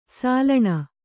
♪ sālaṇa